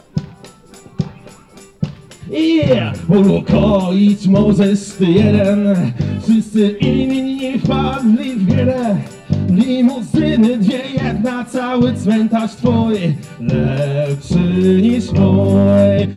Protest przeciw cenzurze w mediach publicznych w formie artystycznego happeningu odbył się wczoraj (20.05) na ełckiej promenadzie.